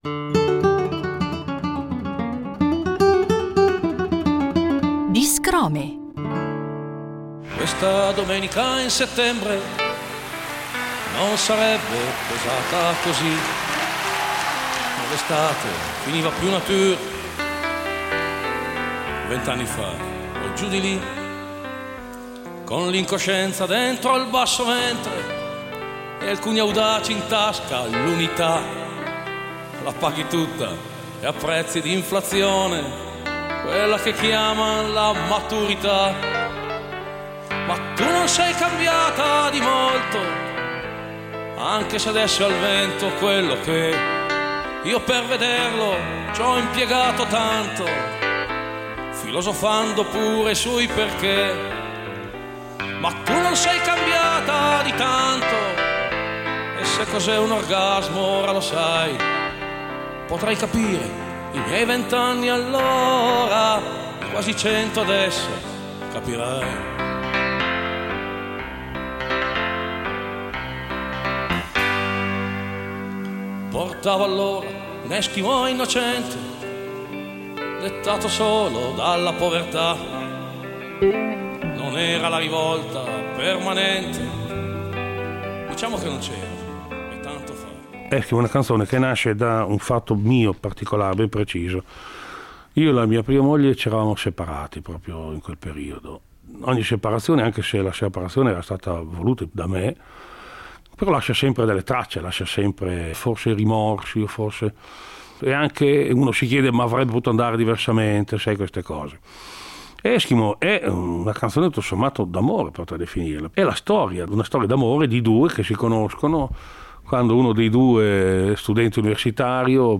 Una preziosa serie di chicche tratte dai nostri archivi: il grande cantautore emiliano Francesco Guccini introduce a modo suo alcune delle sue canzoni più note e amate dal pubblico.